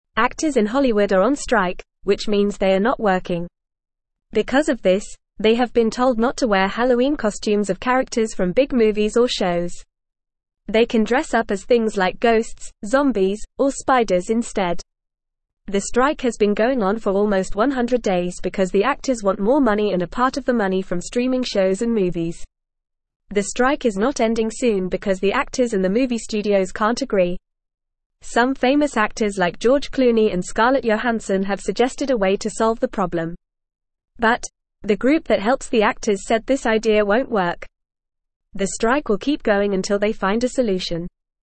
Fast
English-Newsroom-Beginner-FAST-Reading-Hollywood-Actors-on-Strike-No-Movie-Costumes-Allowed.mp3